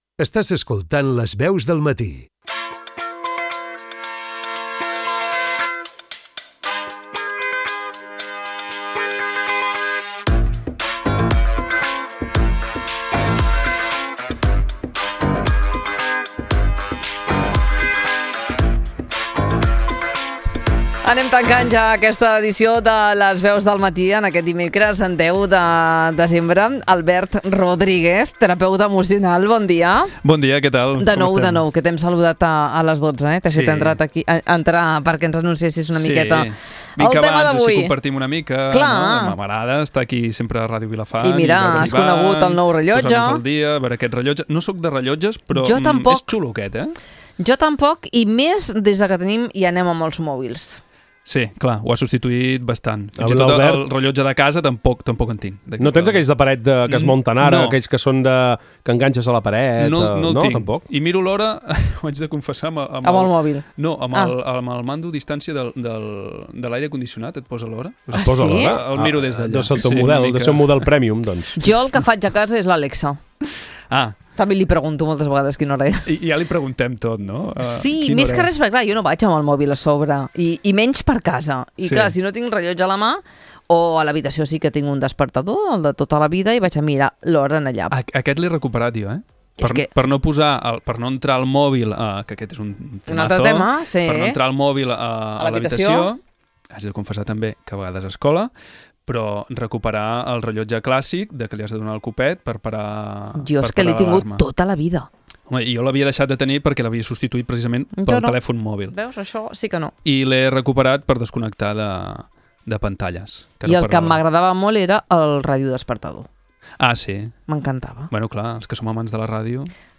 Una conversa per ajudar-nos a mirar endavant amb més claredat, serenor i energia.